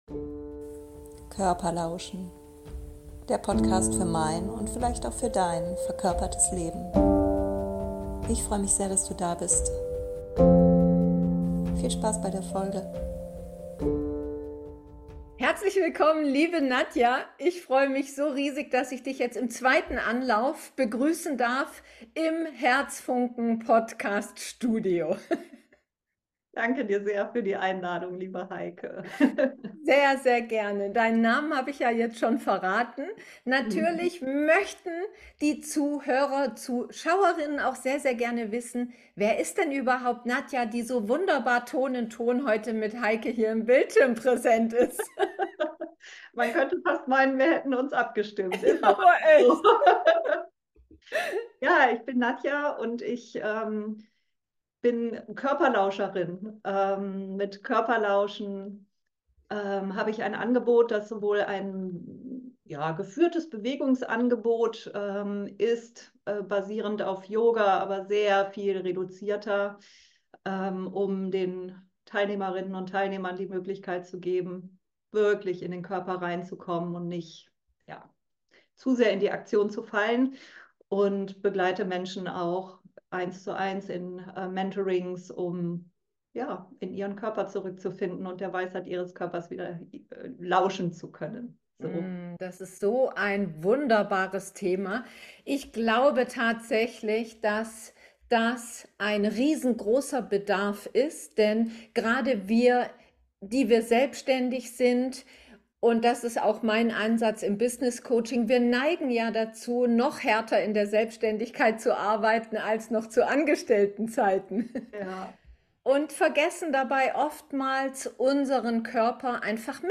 Meine erste Einladung zu einem Podcastinterview!